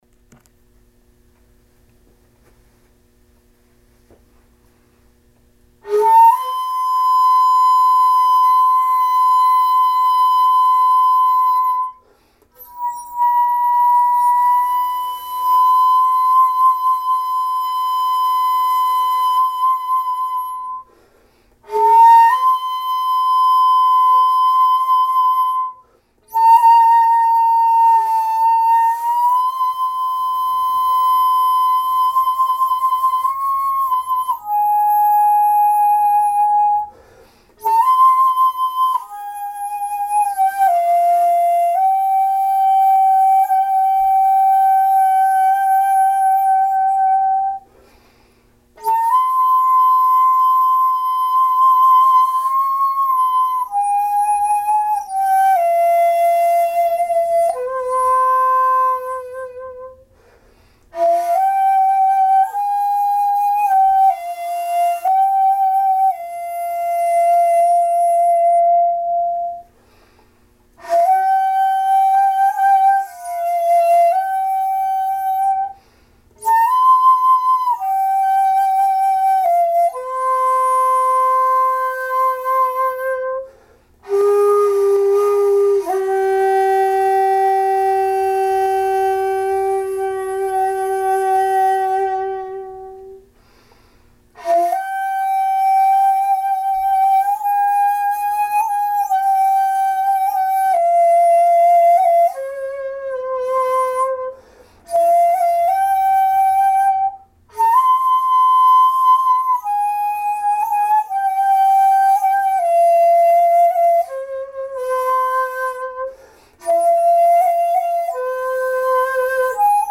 「雲井獅子」は「り」（都山：ハ）を主音とした陰旋法（都節）で甲音で「三のウ・ヒ」に続く「五のヒ中メリ」が印象的な名曲ですので、こんな良い曲が琴古流本曲にないのを残念に思っていました。
稽古場で練習の合間に録音した音源ですが「雲井獅子」をお聴きいただければ幸いです。
地無し延べ竹ゴロ節残し一尺八寸管